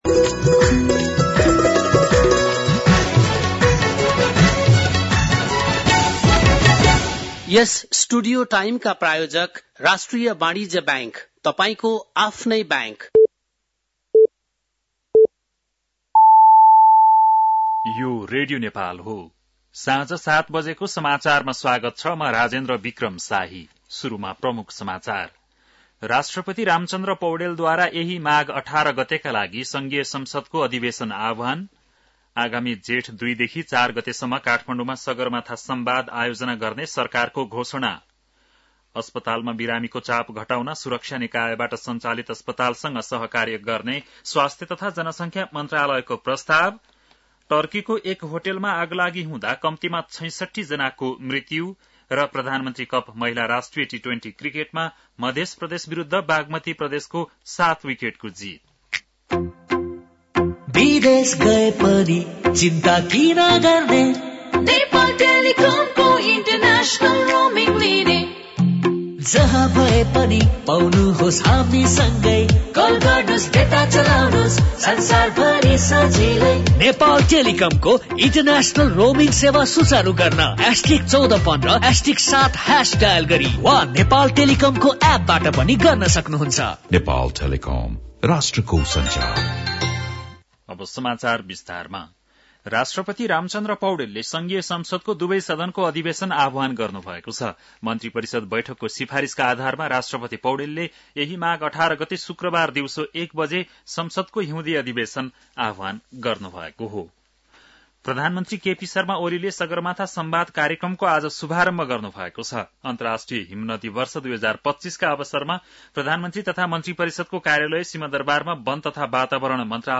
बेलुकी ७ बजेको नेपाली समाचार : ९ माघ , २०८१